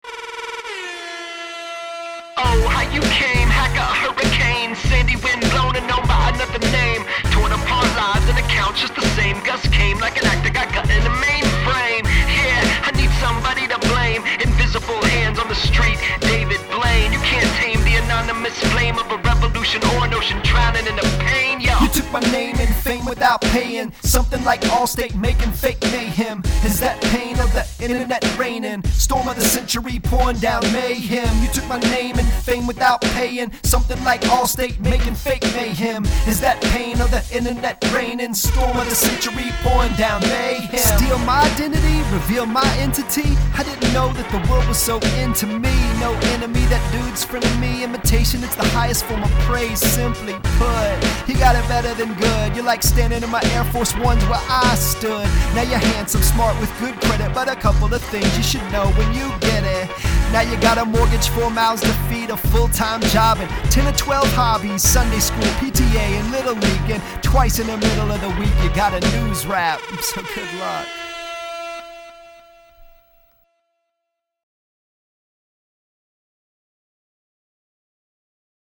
Trust me, it’s no picnic being a news rapper.